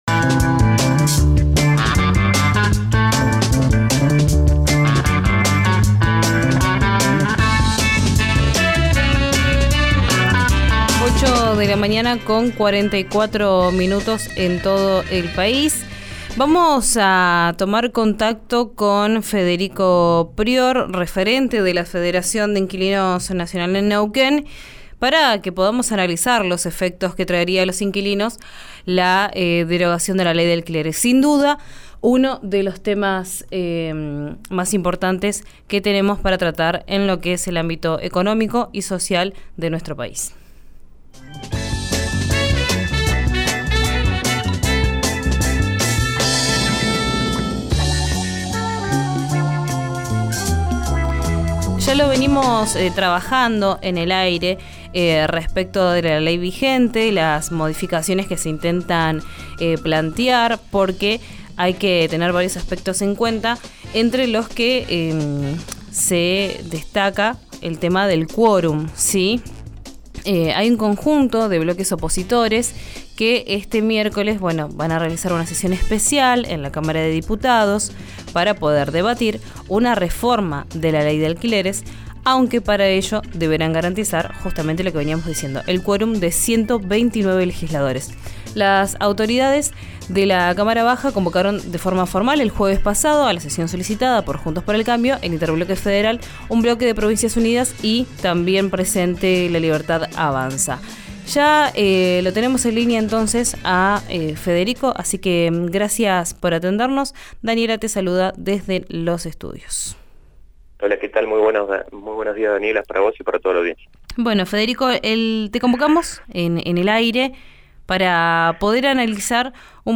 Escuchá la entrevista completa en RÍO NEGRO RADIO.